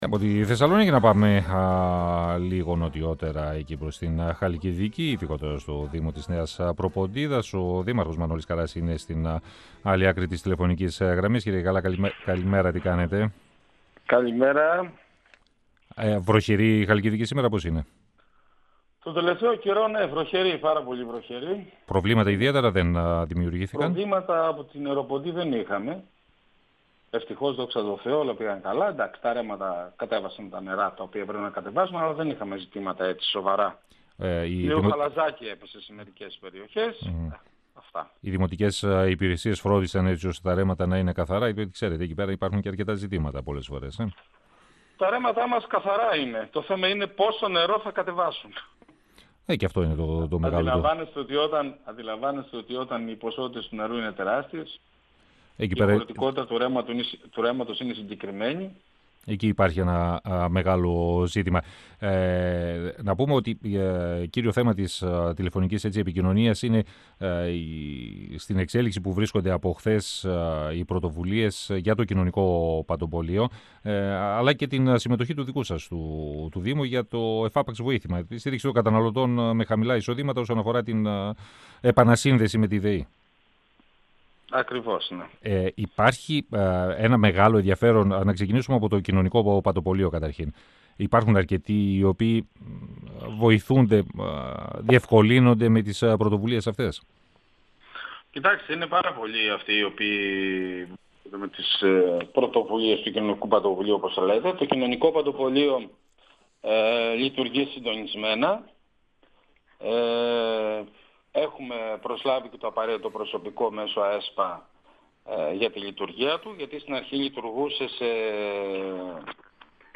Ο δήμαρχος Νέας Προποντίδας Μανώλης Καρράς, στον 102FM του Ρ.Σ.Μ. της ΕΡΤ3
Συνέντευξη